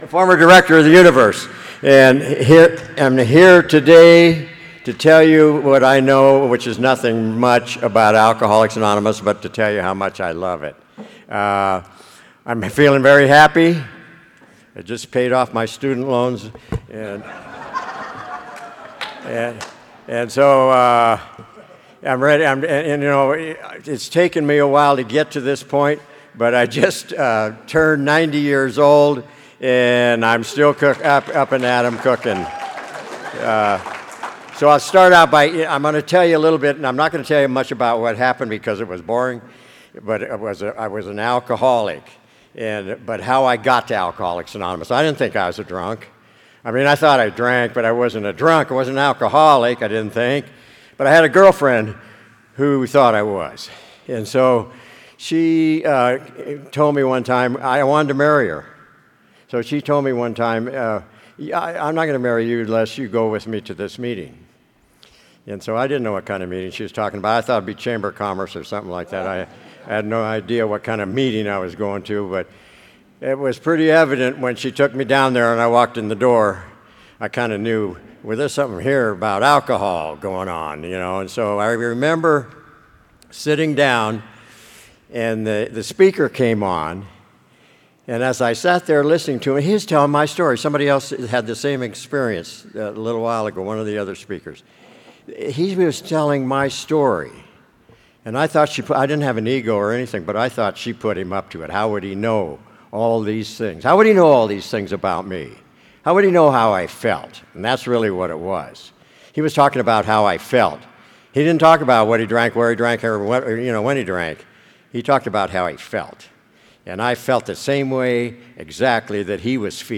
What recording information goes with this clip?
35th Indian Wells Valley Roundup